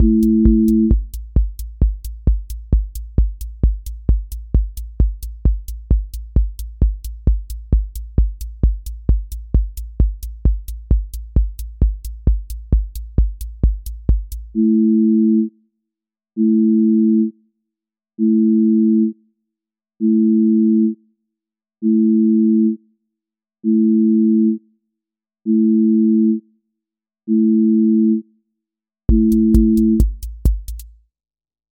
QA Listening Test trance Template: trance_euphoria
• voice_kick_808
• voice_hat_rimshot
• voice_sub_pulse
• fx_space_haze_light
• tone_brittle_edge
Trance ascent with breakdown and drop